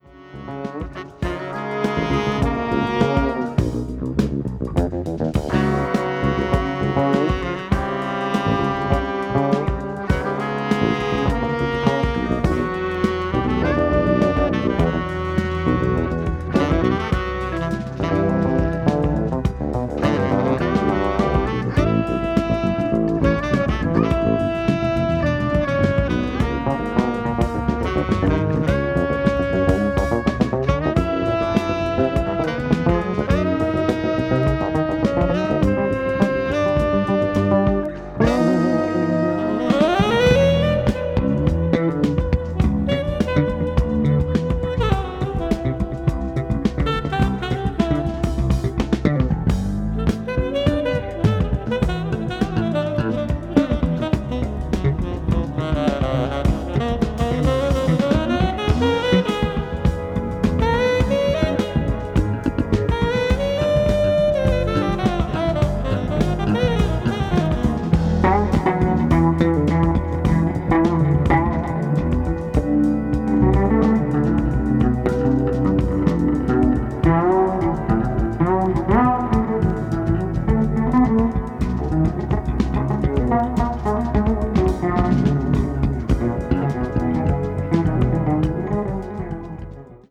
crossover   electric jazz   fusion   jazz groove   jazz rock